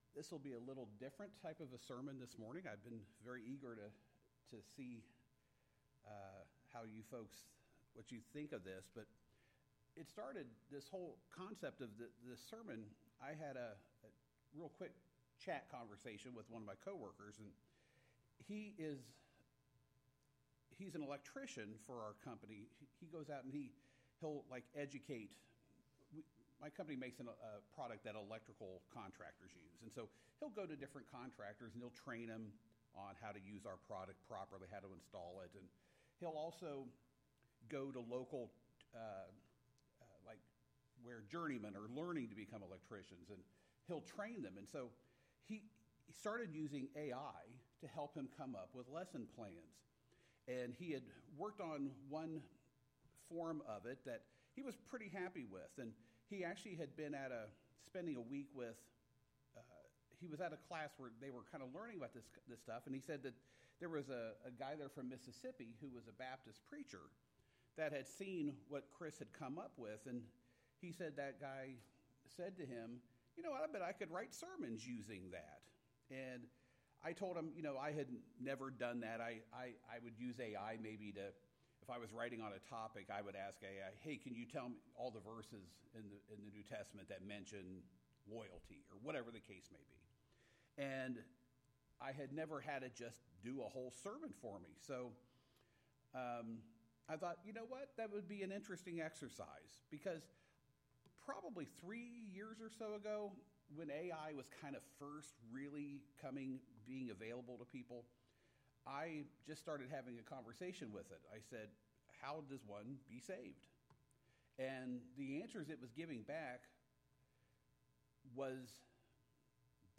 The Unwavering Standard (AI Sermon) – Waynesville Church of Christ